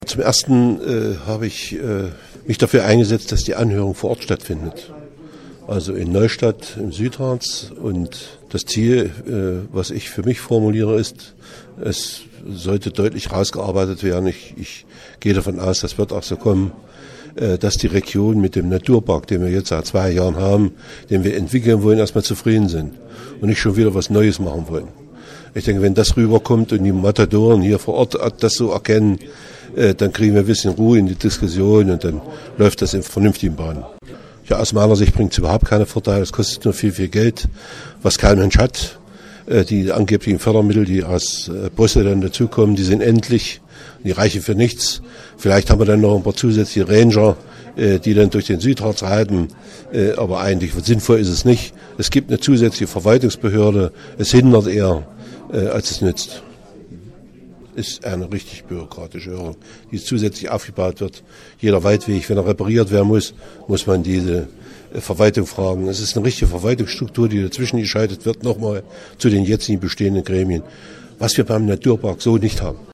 Egon Primas (CDU) sieht keine Vorteile durch ein „Biosphärenreservat Südharz“ und erwartet Rückenwind durch die Anhörung. Das sagte er vorab der nnz.